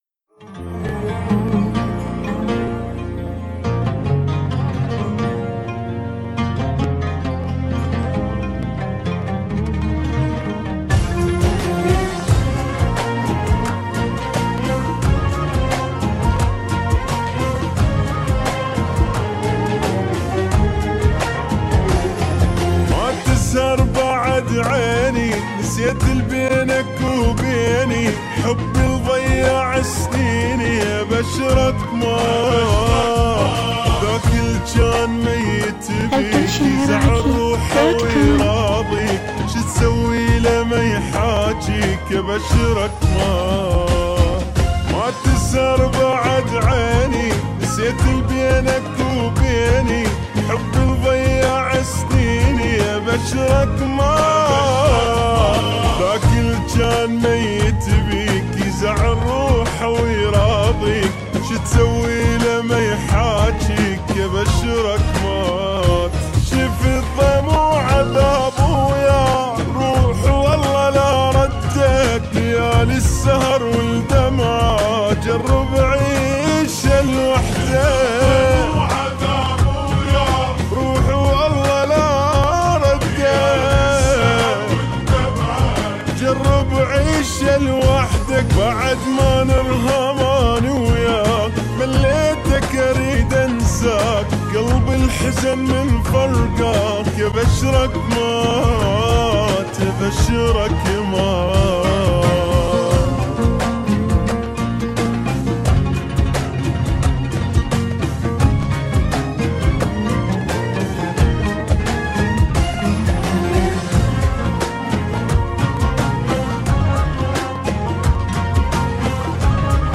اغاني عراقيه 2017